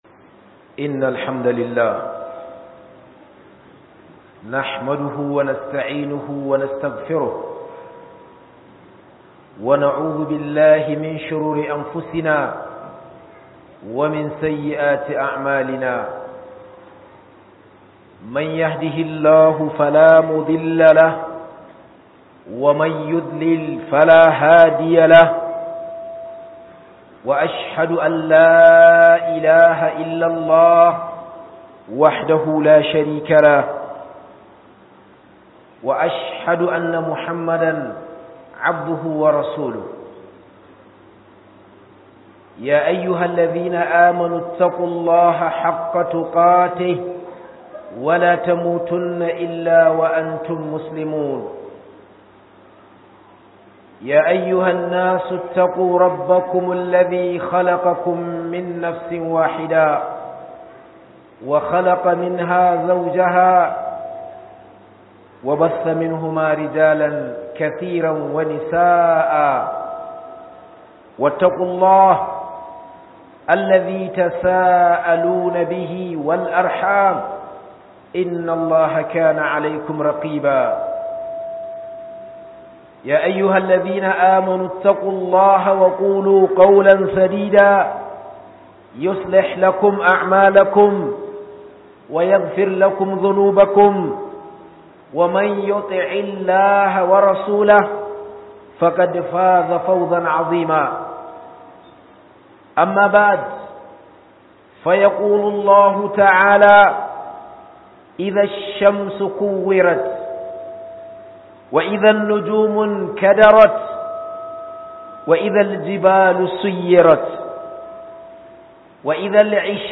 TASHIN QIYAMA - HUDUBA